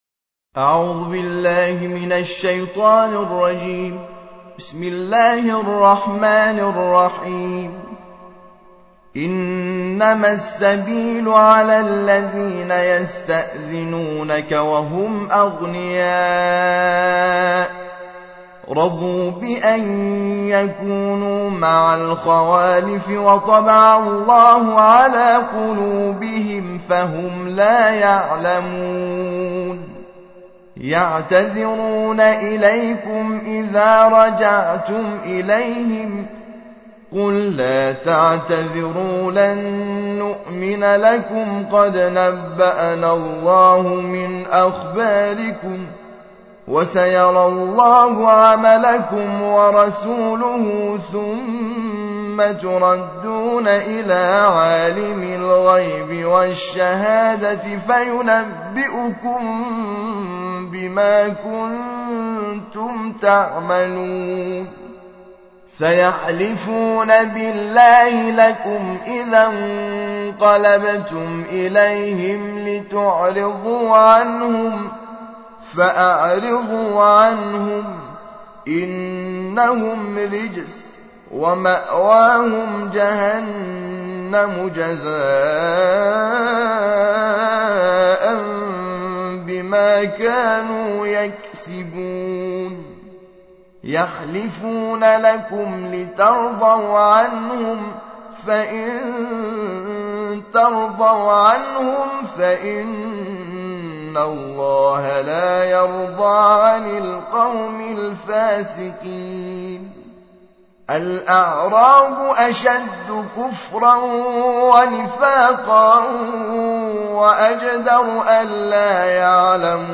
تحميل : الجزء الحادي عشر / القارئ حامد شاكر نجاد / القرآن الكريم / موقع يا حسين